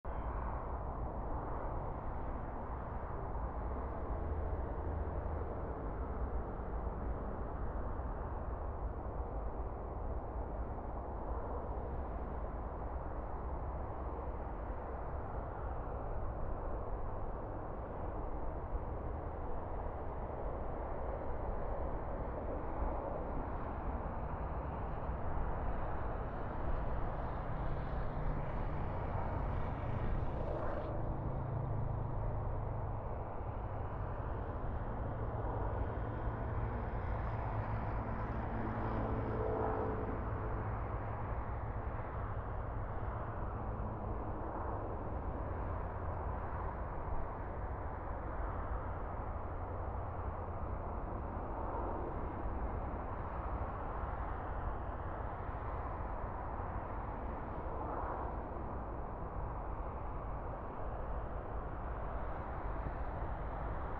highway-right.mp3